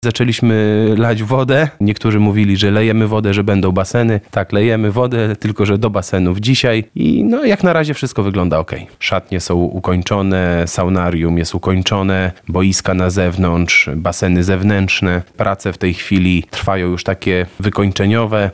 – Od dzisiaj rozpoczyna się testowanie szczelności basenów – mówi w rozmowie z Radiem Lublin zastępca burmistrza Świdnika Marcin Dmowski.